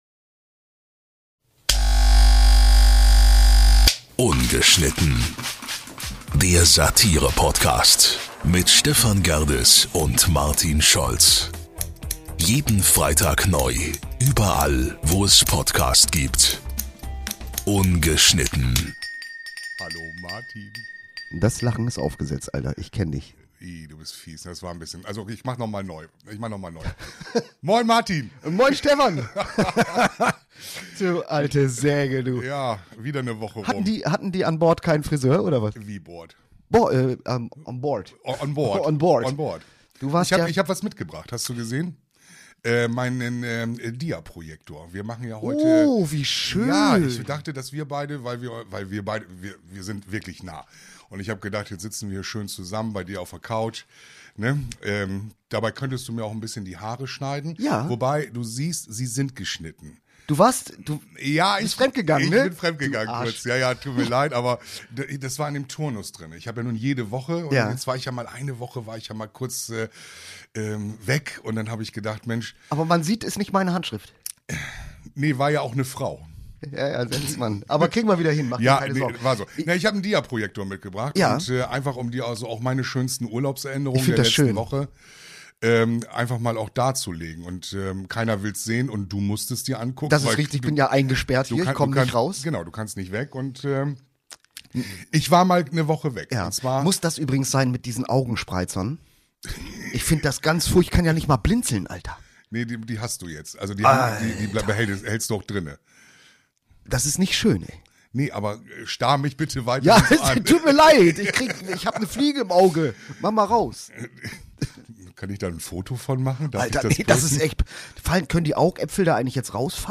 Eine spannende Woche liegt zurück, die Beiden sind zurück aus dem Urlaub und in einem brandneuen Studio unterwegs.